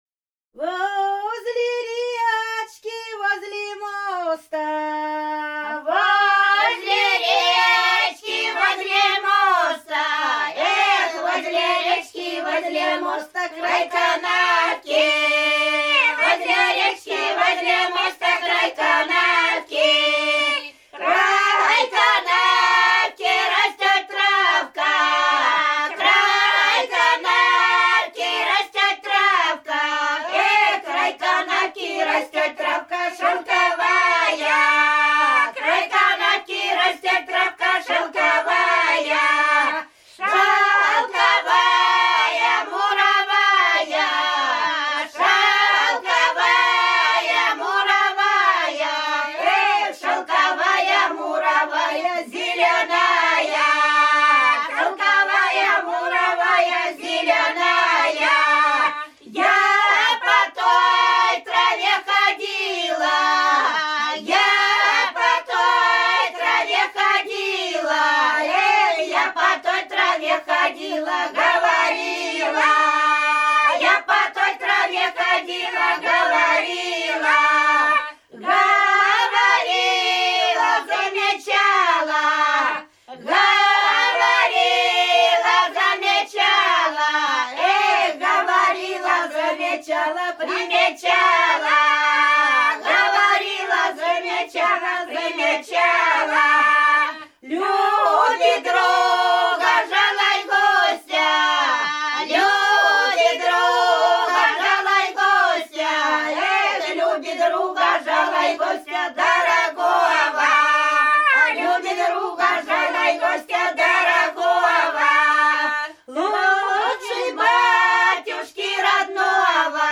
Рязань Кутуково «Возле речки, возле моста», плясовая.